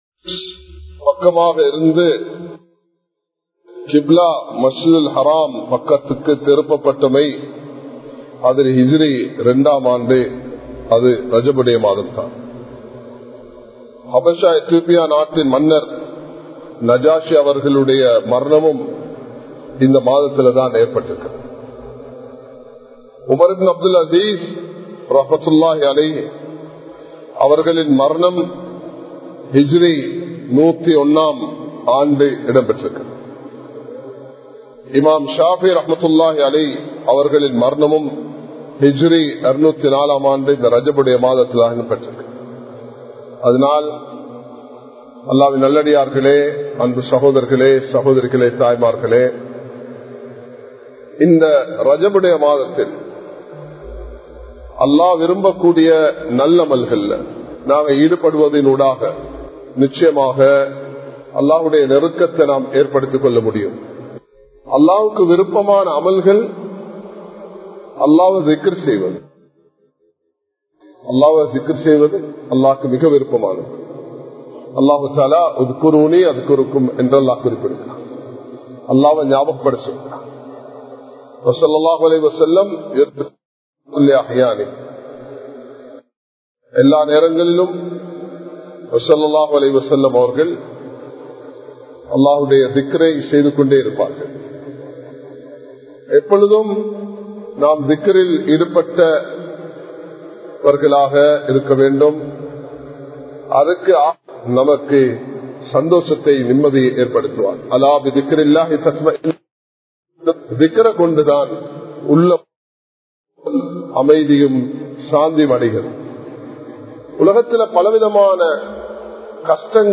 மற்றவர்களை மதியுங்கள் | Audio Bayans | All Ceylon Muslim Youth Community | Addalaichenai
Kollupitty Jumua Masjith